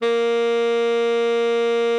Added more instrument wavs
bari_sax_058.wav